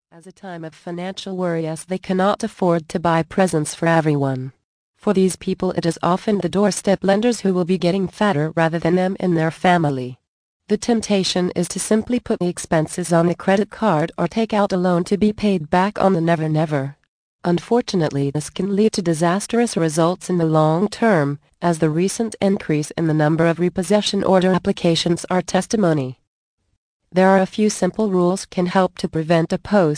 The Magic of Christmas audio book. Vol. 7 of 10 - 63min